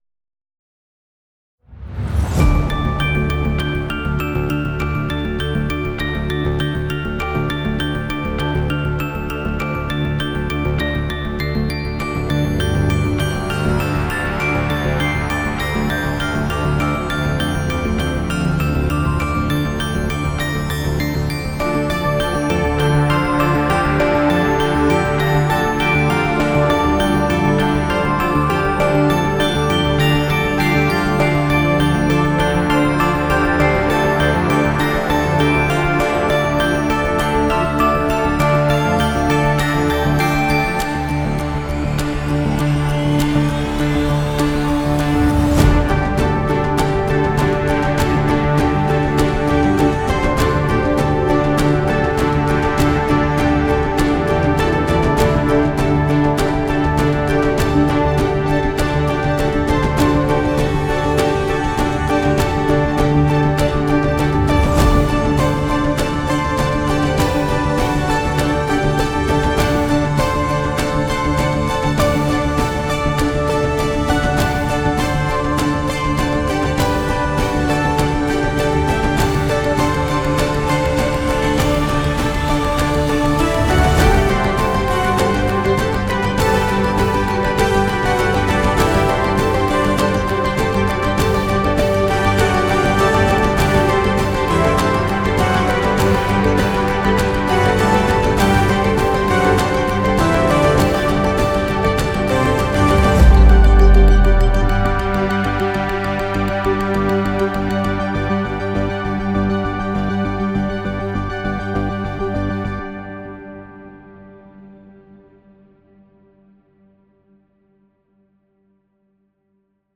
Music for the clockworks section of a roblox game.
There are points of impact.